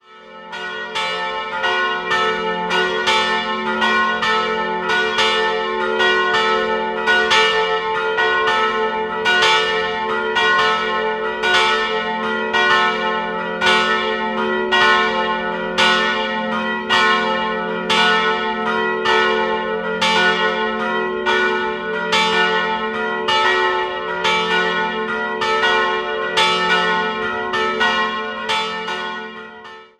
Heute steht das Gebäude unter Denkmalschutz. 3-stimmiges Geläut: fis'-a'-h' Die Glocken wurden 1948 von der Gießerei Schilling in Apolda gegossen.